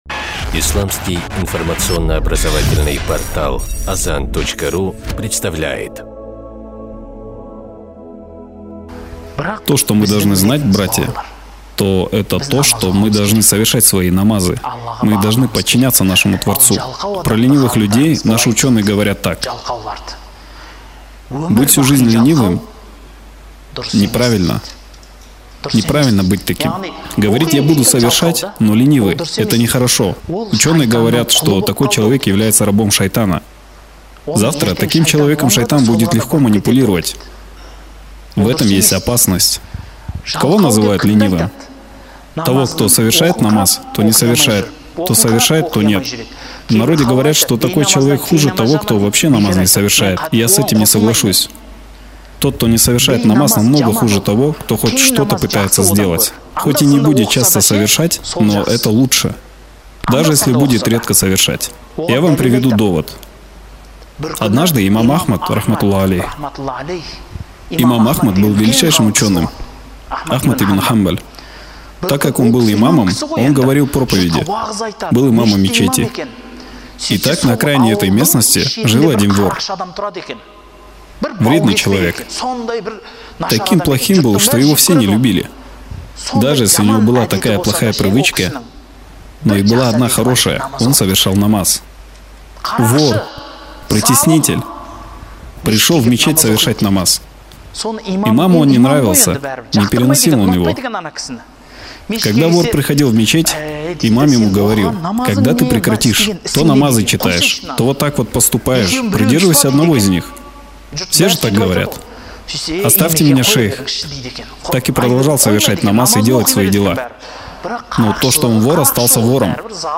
Лектор
Устаз, исламский теолог